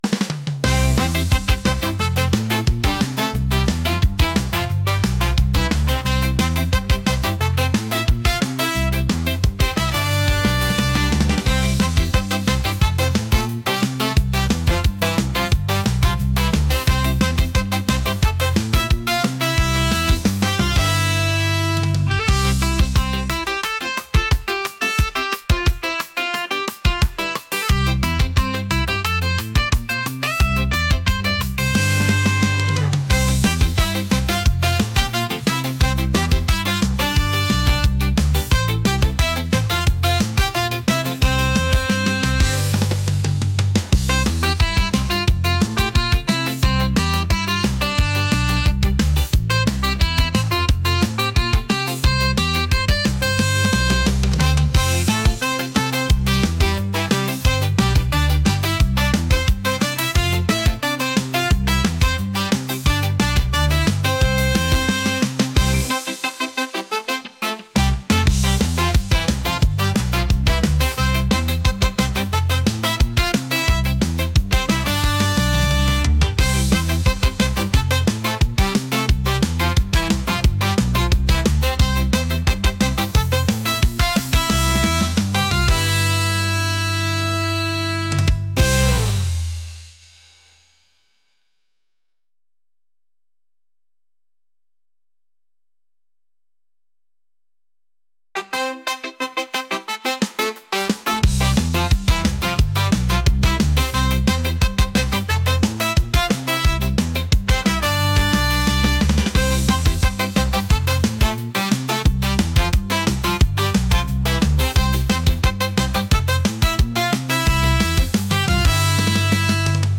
ska | upbeat | lively